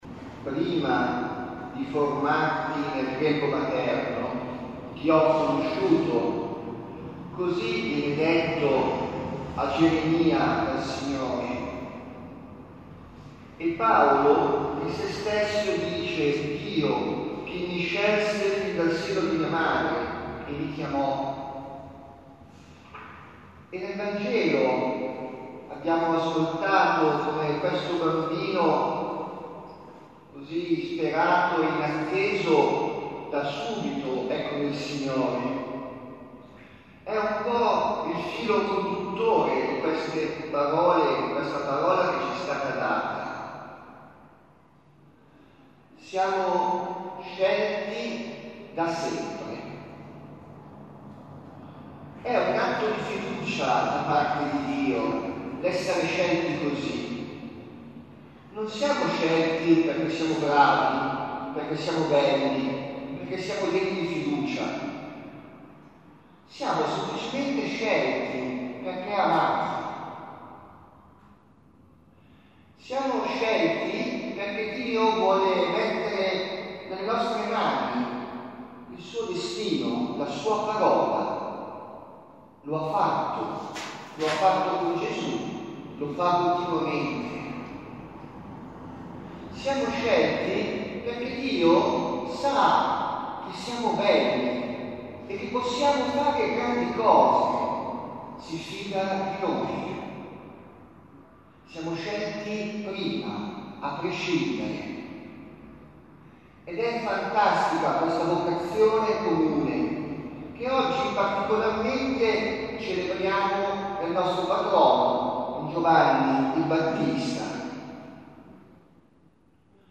Categoria: Omelie